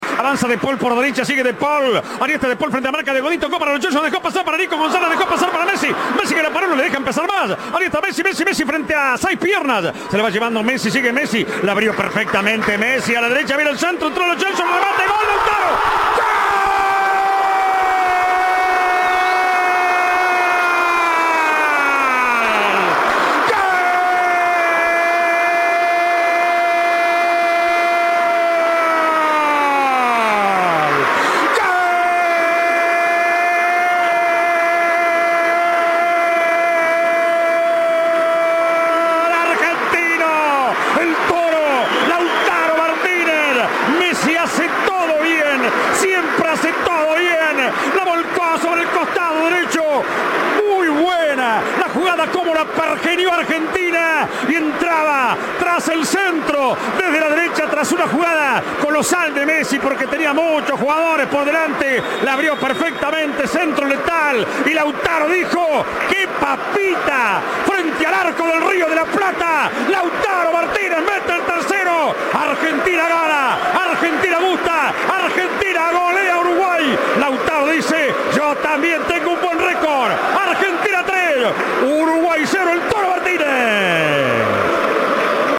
Reviví el vibrante relato de los tres goles de Argentina